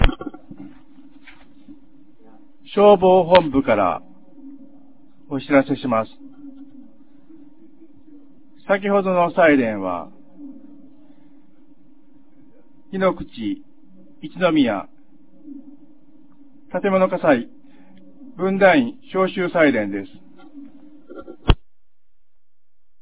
2025年01月01日 08時31分に、安芸市よりへ放送がありました。